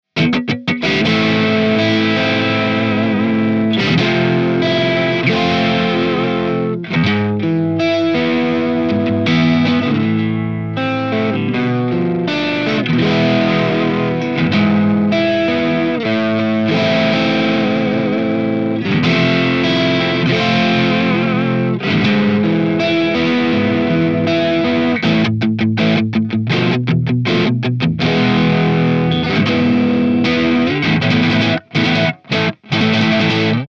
The JCM900 pack includes captures ranging from clean tones to heavily distorted and everything in between plus my personal YouTube IR that I use in my demos are also included.
Chords
RAW AUDIO CLIPS ONLY, NO POST-PROCESSING EFFECTS